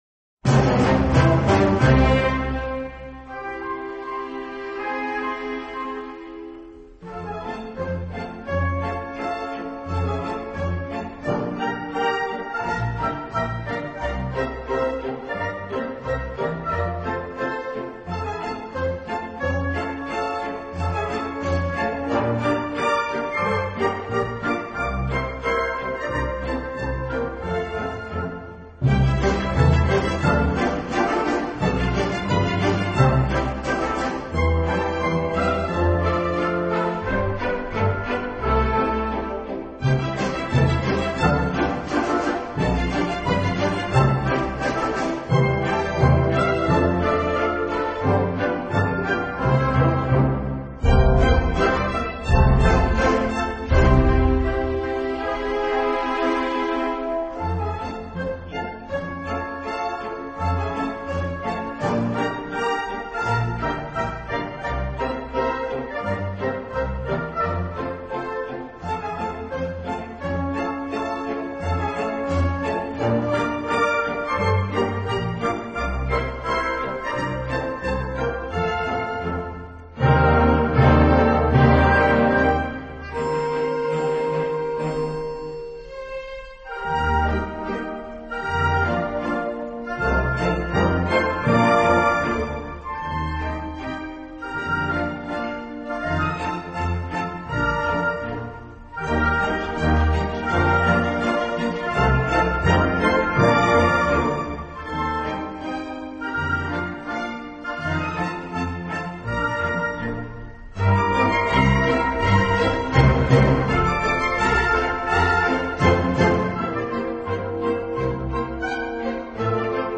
音乐类型：Classic 古典
音乐风格：Classical,Waltz